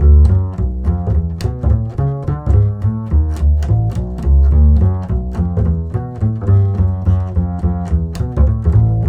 -JP WALK D.wav